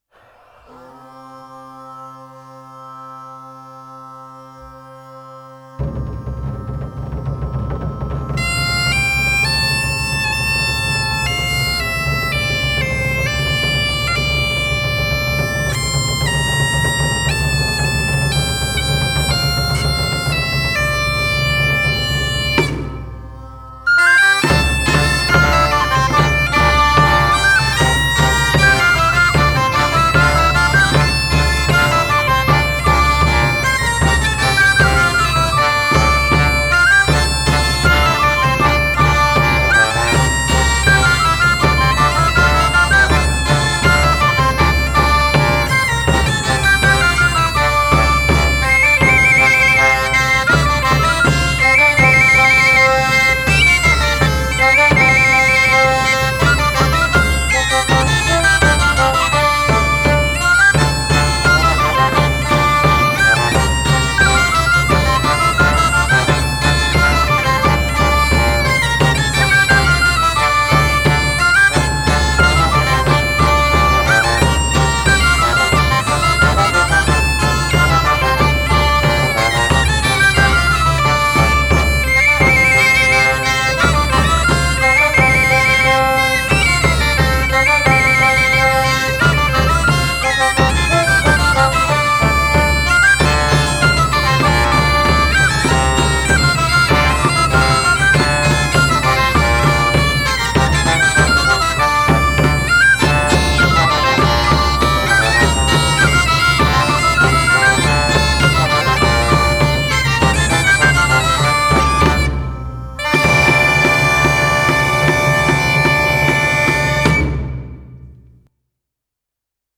CUCAFERA DE TARRAGONA Ministrers El Tecler
Santa Tecla Tarragona